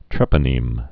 (trĕpə-nēm)